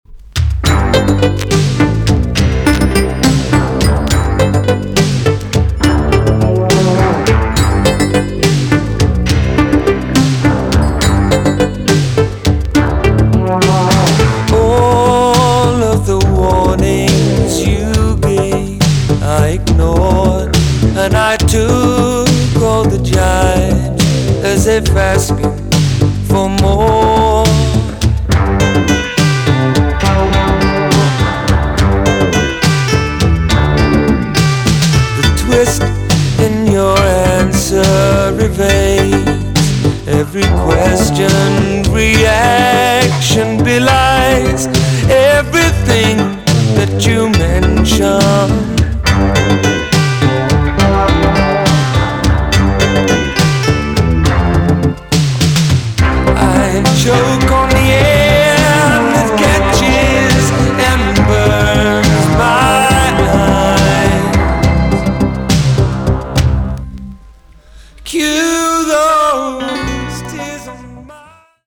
EX- 音はキレイです。